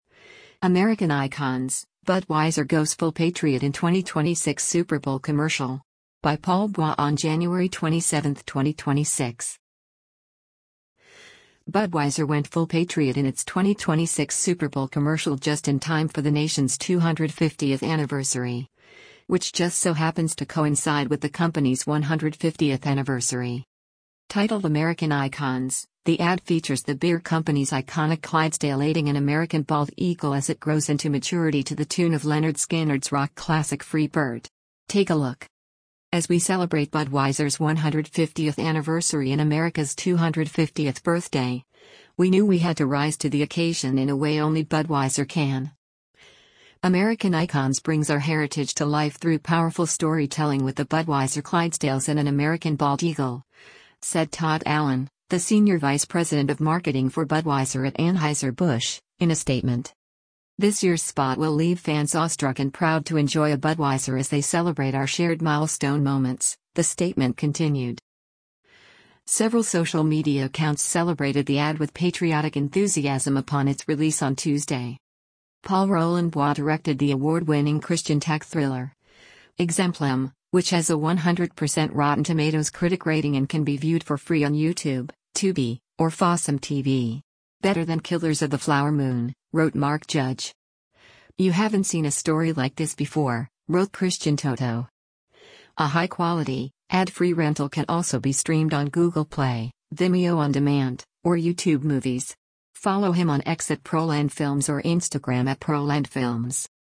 rock classic